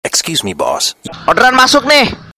Nada dering Gojek yang terpasang pada HP mitra atau driver sering kali terasa melekat di kuping pelanggan.
4. Nada Dering Orderan Masuk Nih Bos
orderan-masuk-nih-bos.mp3